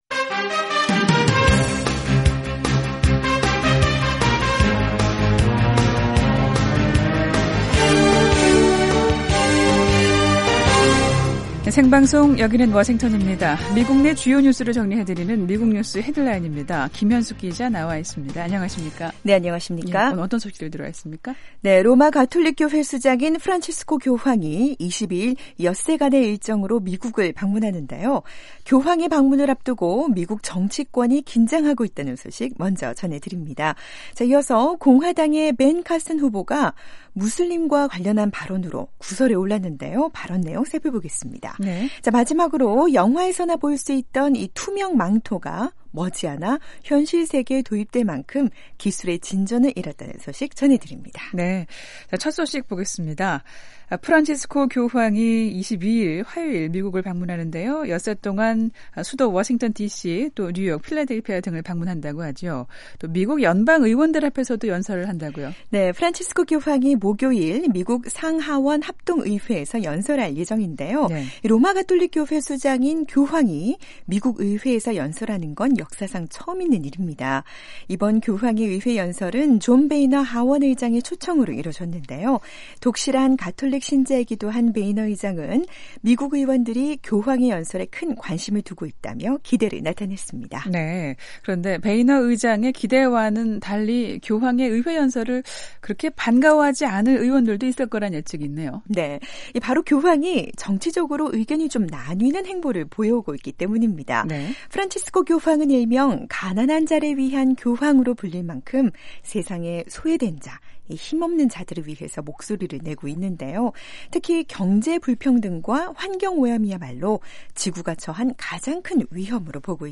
미국 내 주요 뉴스를 정리해 드리는 ‘미국 뉴스 헤드라인’입니다. 로마 가톨릭 교회 수장인 프란치스코 교황이 22일 엿새간의 일정으로 미국을 방문하는데요.